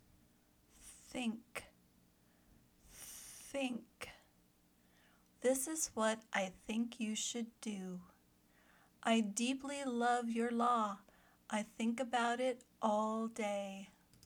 /θɪŋk/  (verb)